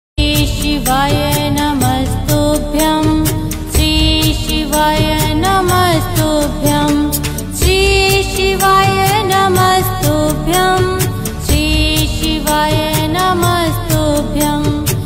शिव भजन रिंगटोन